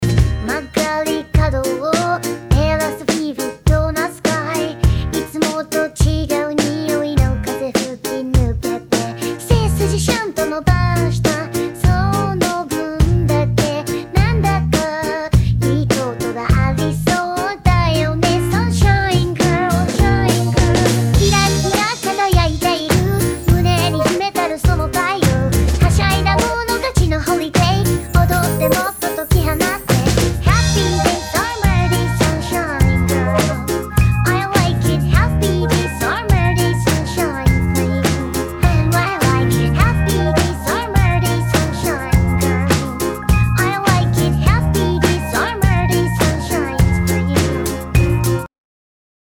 翻唱效果